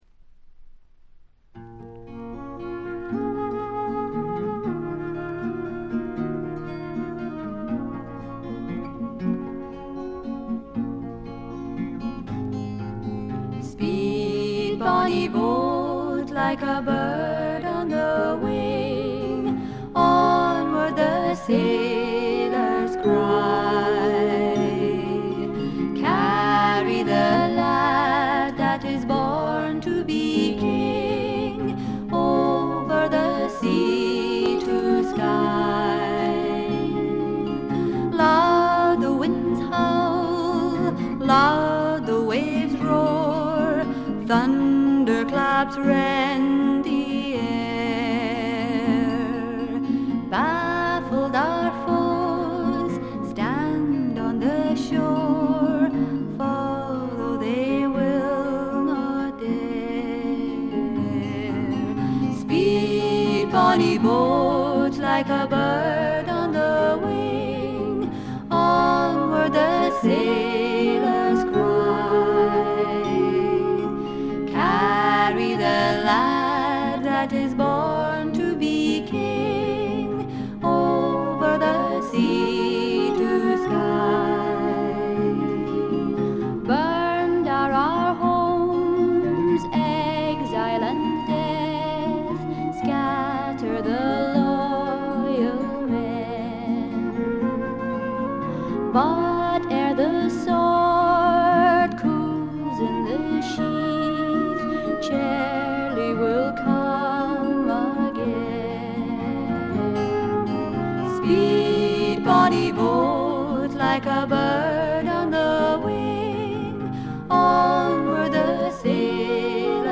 軽微なチリプチやバックグラウンドノイズ、散発的なプツ音少々。
内容はまさしく天使の歌声を純粋に楽しめる全14曲です。
アレンジはあくまでもフォークであってギターのアルペジオ主体の控え目なものです。
試聴曲は現品からの取り込み音源です。